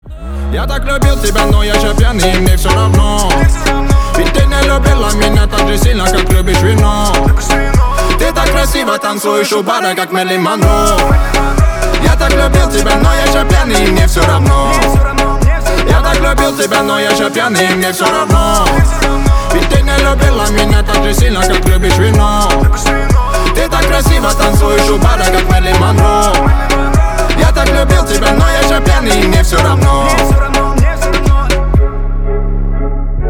• Качество: 320, Stereo
ритмичные
громкие
зажигательные
веселые